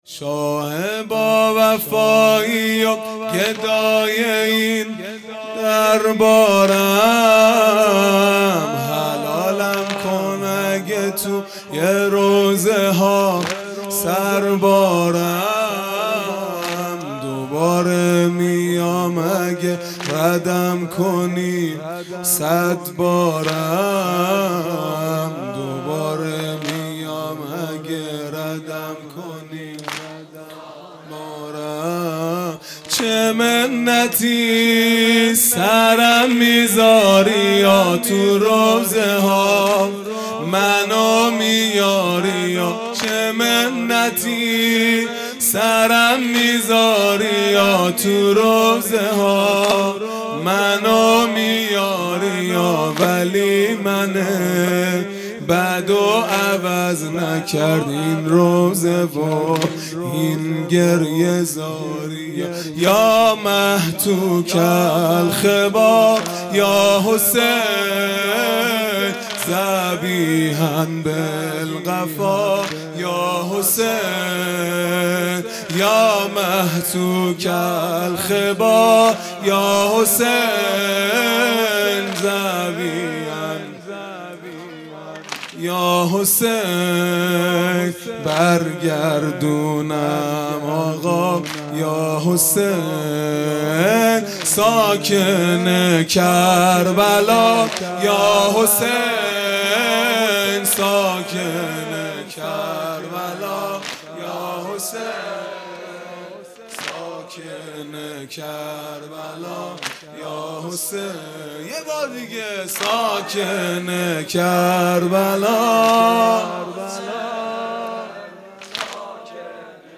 واحد | شاه با وفایی و گدای این دربارم | جمعه ۲۲ مرداد ۱۴۰۰
دهه اول محرم الحرام ۱۴۴۳ | شب پنجم | جمعه ۲2 مرداد ۱۴۰۰